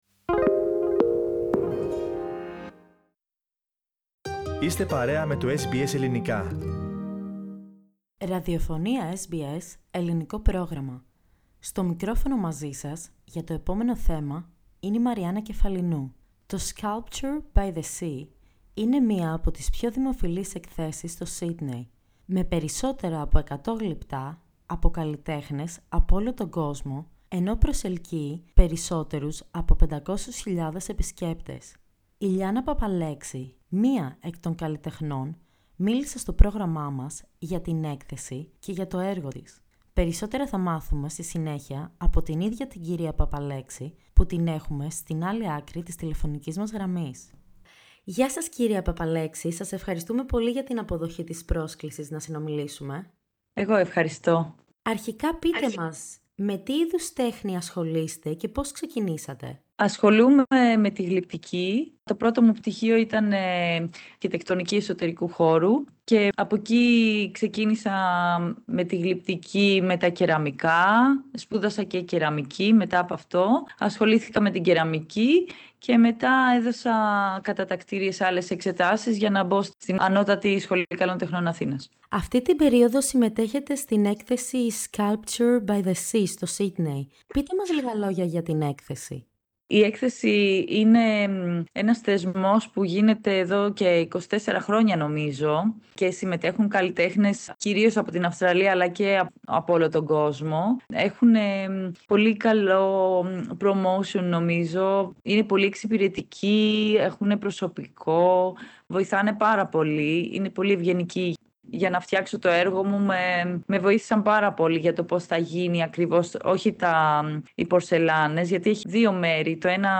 Ακούστε ολόκληρη τη συνέντευξη στο podcast.